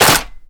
gun_chamber_jammed_03.wav